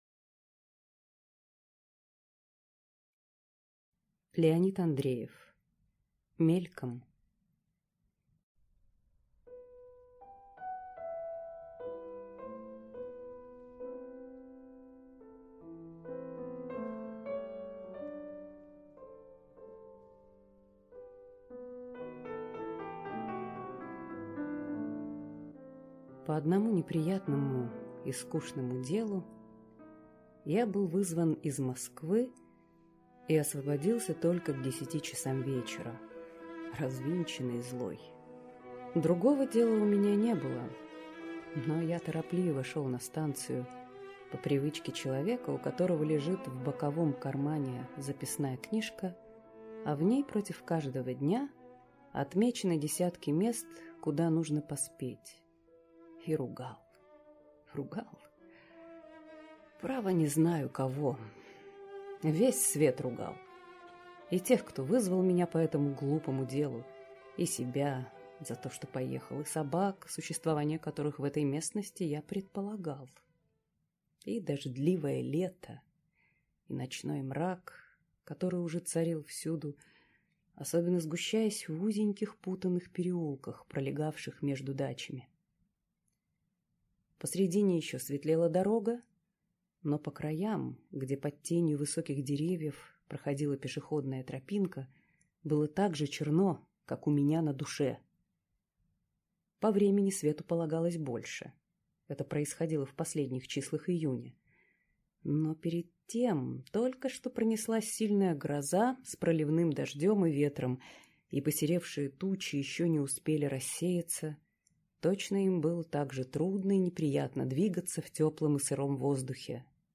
Аудиокнига Мельком | Библиотека аудиокниг